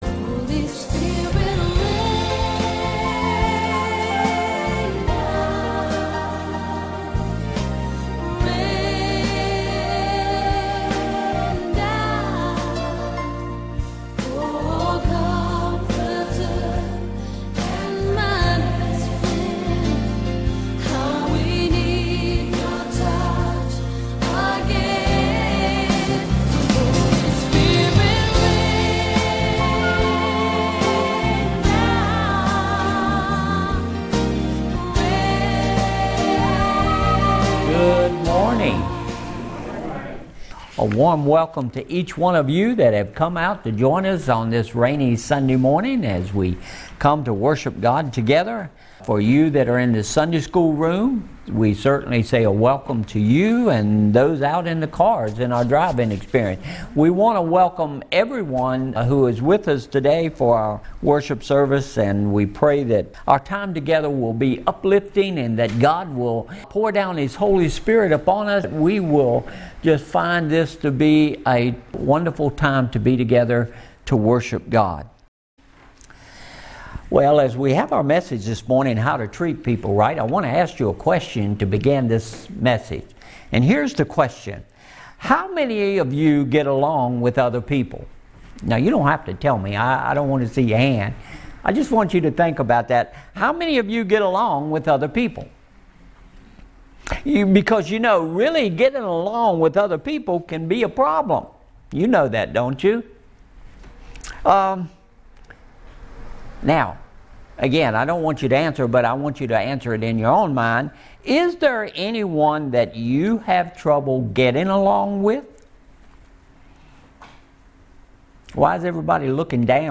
Feb 28, 2021 How to Treat People Right MP3 Notes Discussion Sermons in this Series Sermon Series How to Face Our Future!